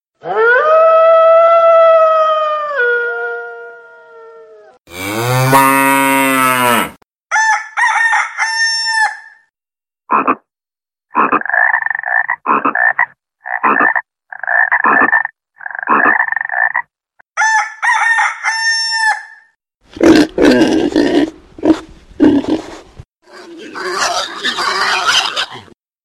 Вам нужно пройти маршрут по звукам животных.
волк
корова
петух
лягушка
свинья